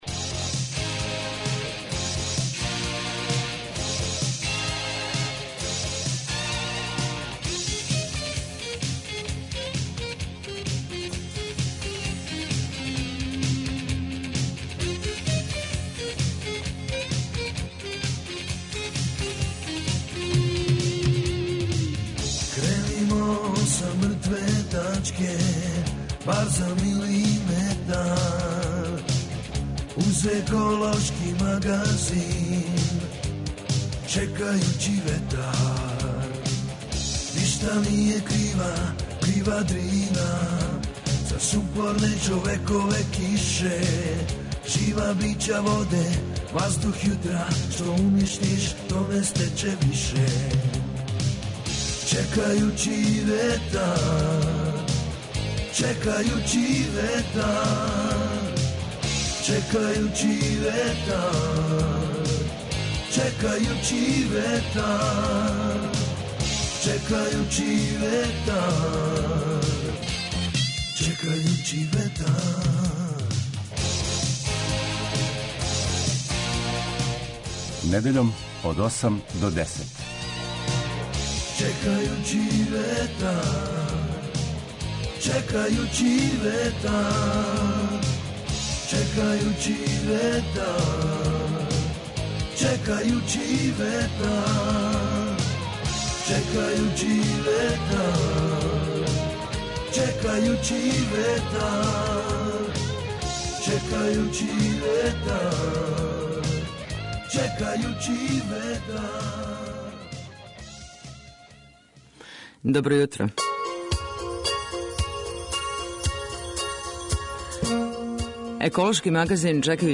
Тридесет три године еколошког магазина
Чућете дах емисије Чекајући ветар са самог почетка њеног емитовања, а ове недеље емисија је отворена и за вас који је данас слушате - ваша размишљања на тему екологије и заштите животне средине. У току је израда Закона о финансирању локалне самоуправе.